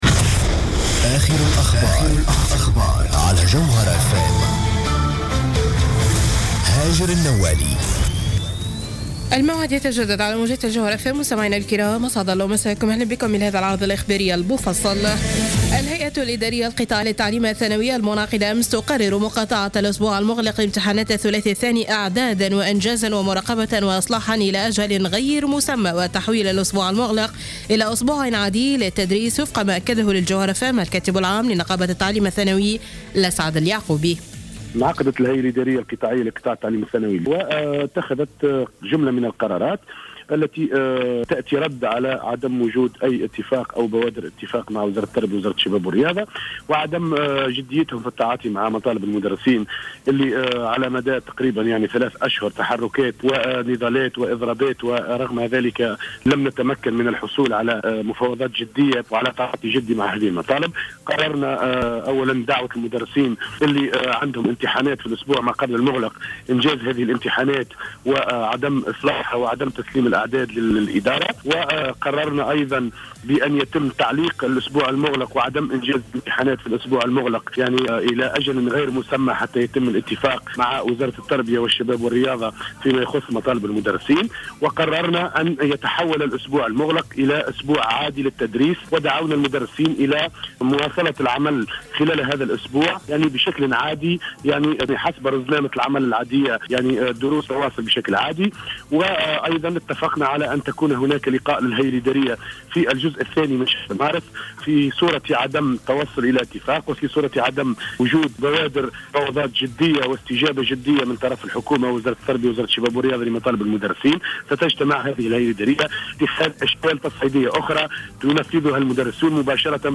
نشرة أخبار منتصف الليل ليوم الإثنين 23 فيفري 2015